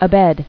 [a·bed]